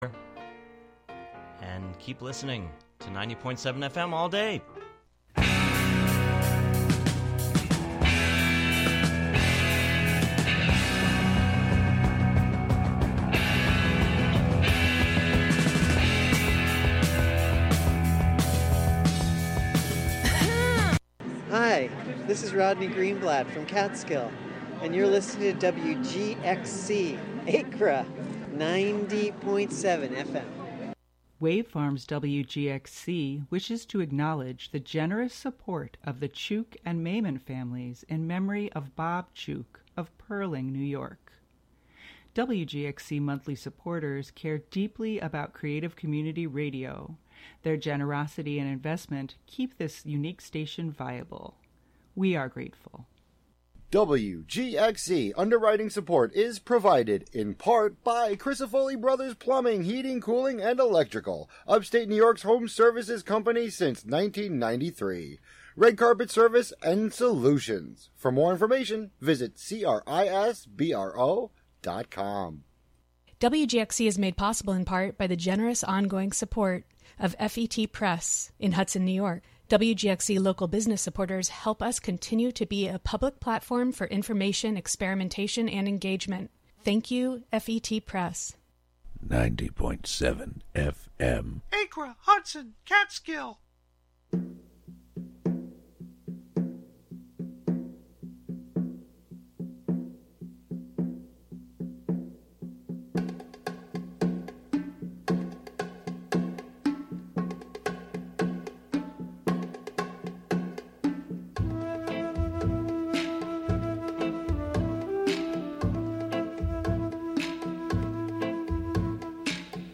With LGBTQ+ artists from Latin America, Spain, and Portugal.
From Tango to Flamenco and all Latinx music in between.